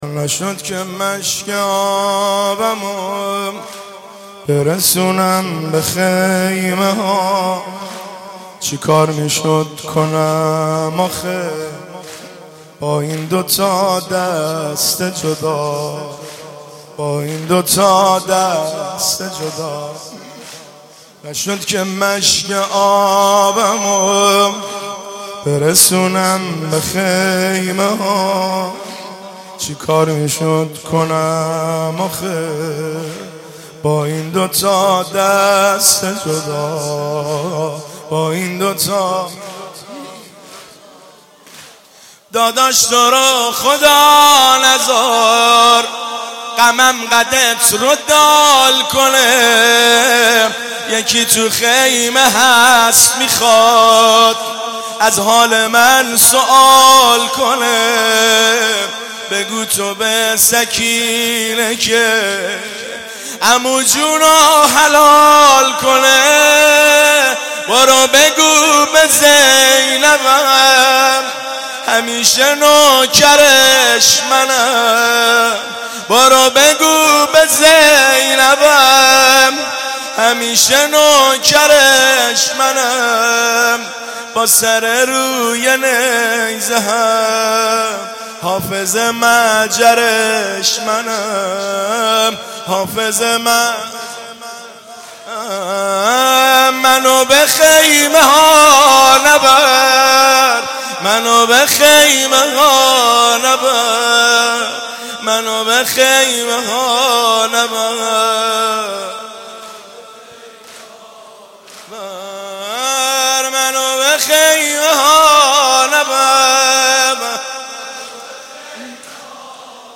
محرم 96 - زمینه - نشد که مشک آبمو برسونم به خیمه ها